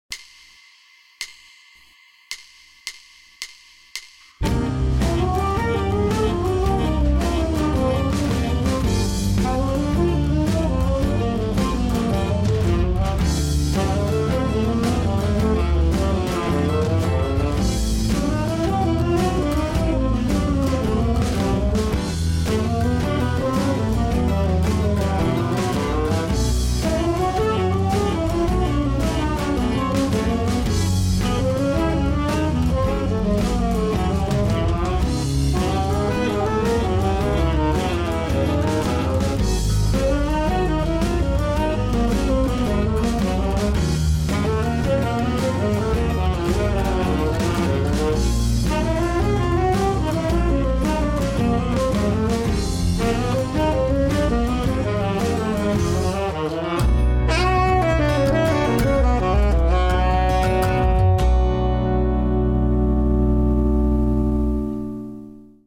Here’s what emerged after an hour or so;  a little chromatic meander that caught my imagination.  As harmonized in this sketch, it forms a Dorian setting reminiscent of “So What,” “Little Sunflower,” “Jeanine,” or “Invitation.”  It could also have been harmonized as a ii-V progression in C major modulating to D minor.